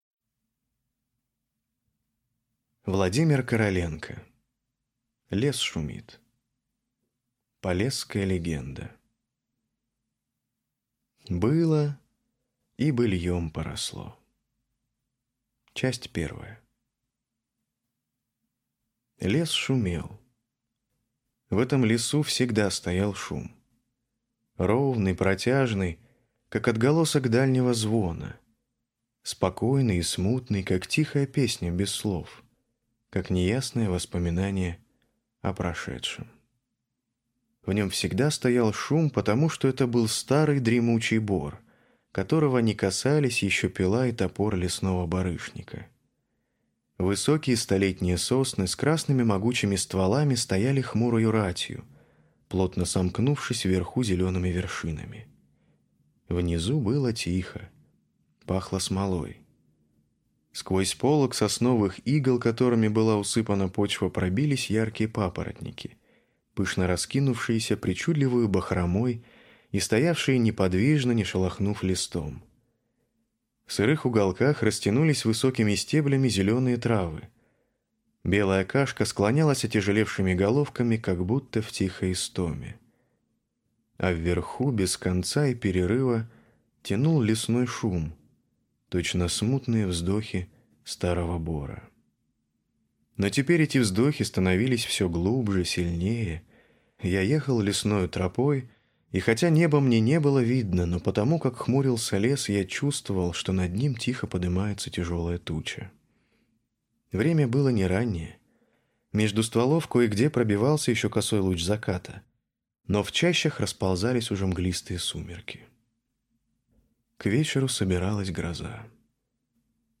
Аудиокнига Лес шумит | Библиотека аудиокниг